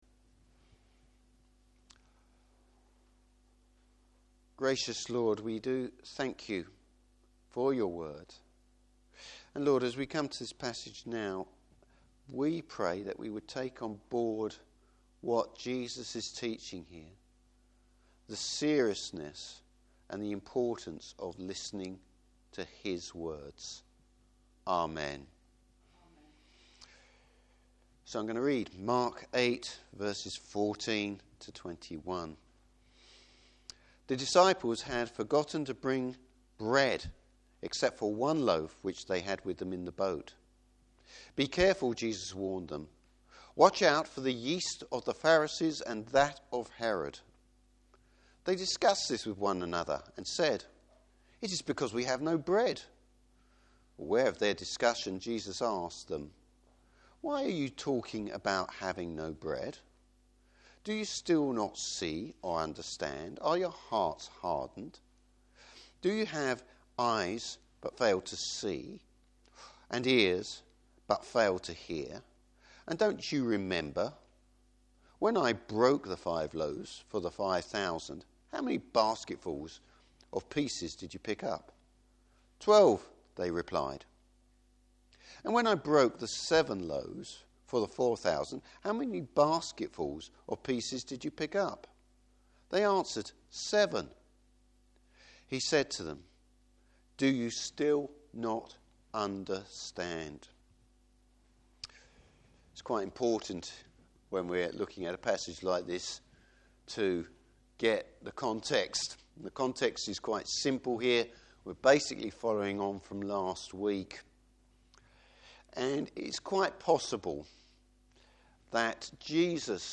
Service Type: Morning Service Jesus highlights the Disciple’s lack of understanding.